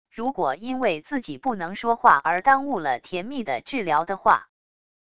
如果因为自己不能说话而耽误了田蜜的治疗的话wav音频生成系统WAV Audio Player